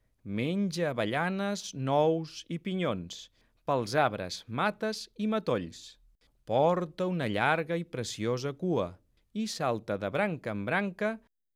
Es tracta d'una endevinalla desordenada.